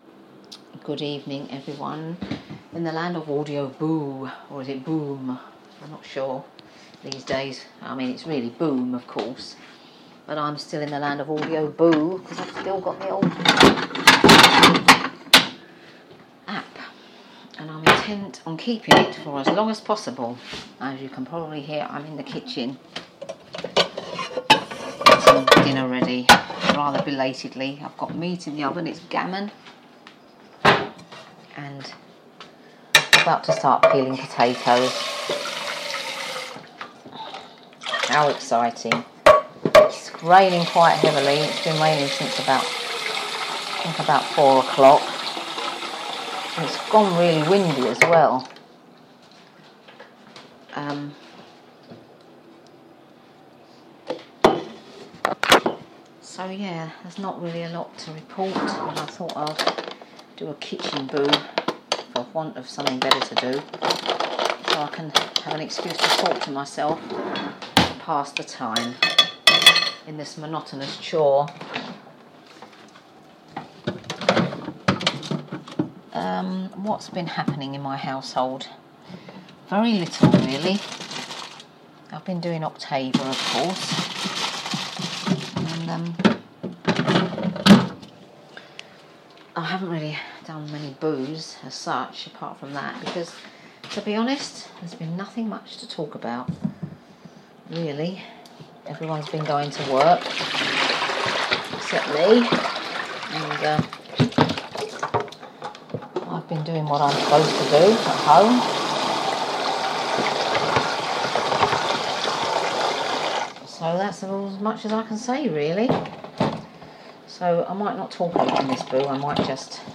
Hello from the kitchen on this rainy Sunday evening